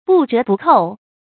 不折不扣 bù zhé bù kòu
不折不扣发音
成语正音折，不能读作“zé”。